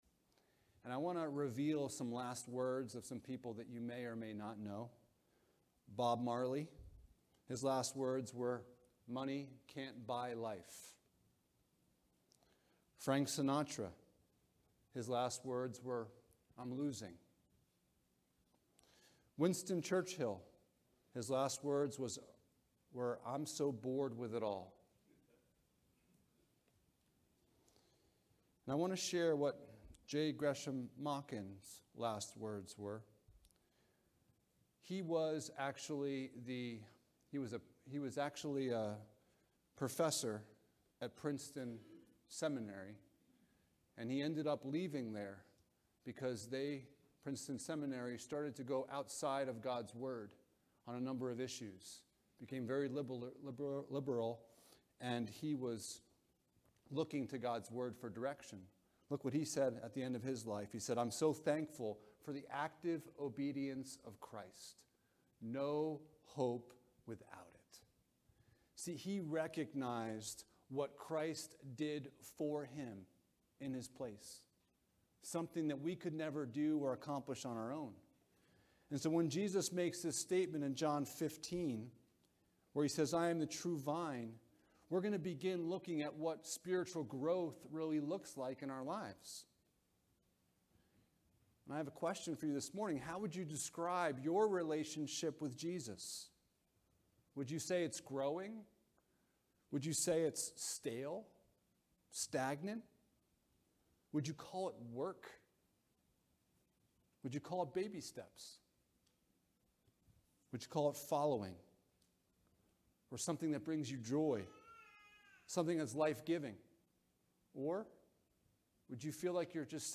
The Great I Am Passage: John 15: 1-11 Service Type: Sunday Morning « I AM the Way